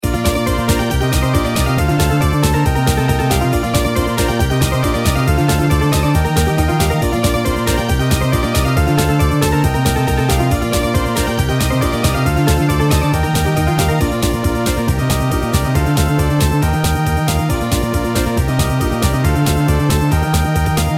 I made some background music for mobile games..